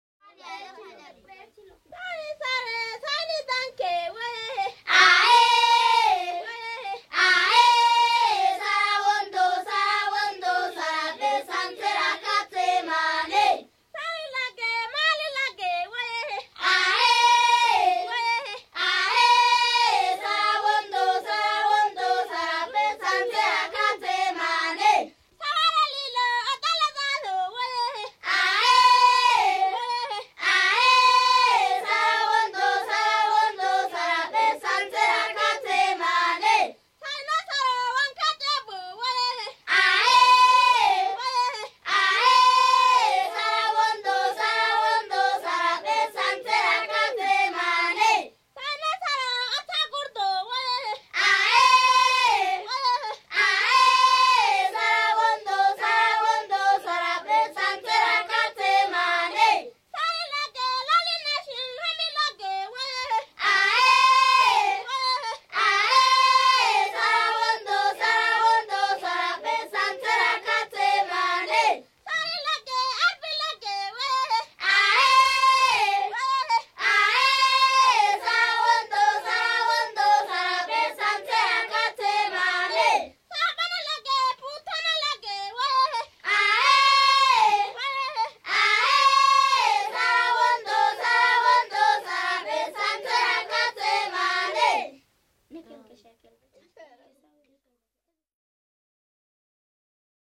Zu Beginn: Volksmusik singende Kinder – damit kann nichts schief gehen und ich erobere dein Herz im Sturm:
15-Chant-de-filage-des-jeunes-filles-Dorzé.m4a